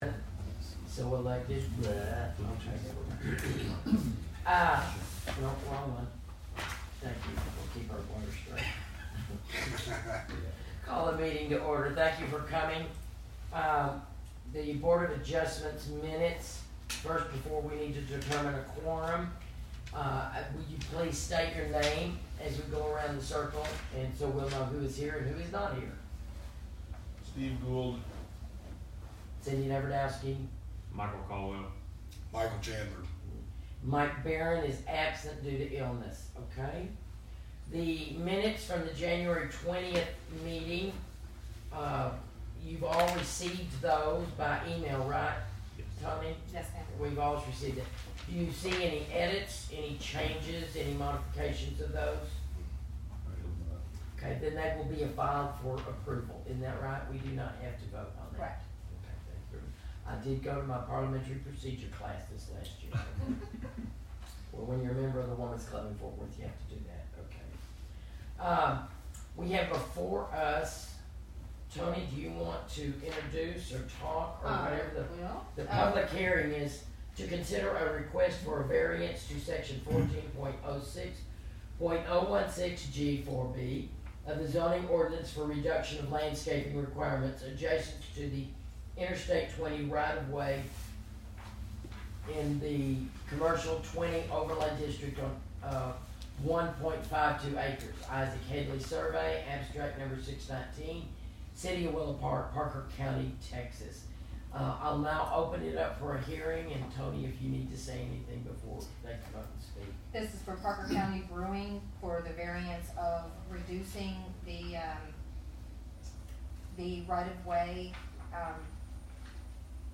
Location and Time: El Chico City Hall, 120 El Chico Trl., Ste A, Willow Park, TX 76087, 6PM